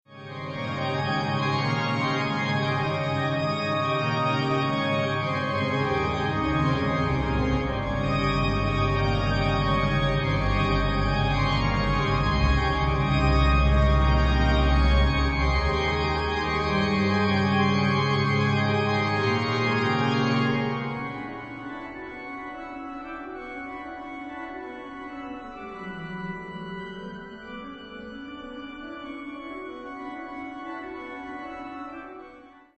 Sound Extracts From the Rieger Organ